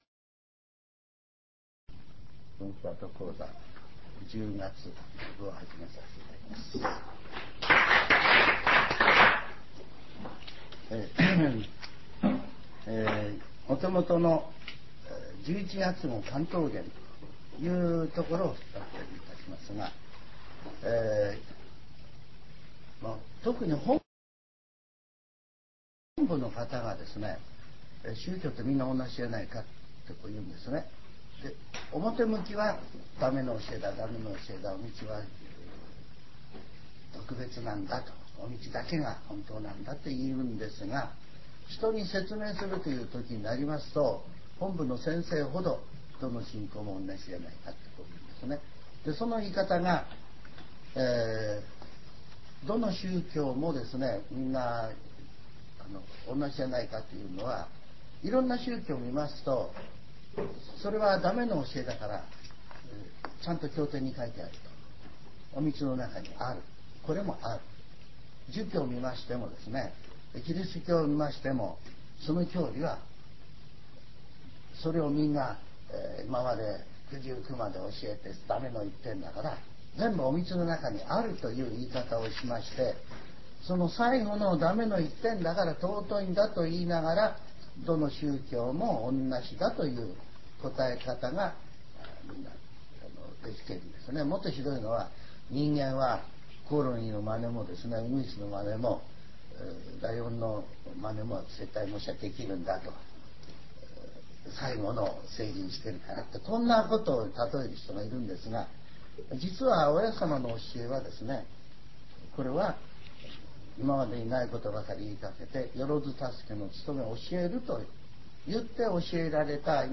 全70曲中1曲目 ジャンル: Speech